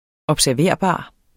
Udtale [ ʌbsæɐ̯ˈveɐ̯ˀˌbɑˀ ]